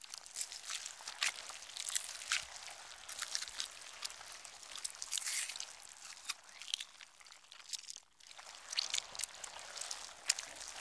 auto_idle.wav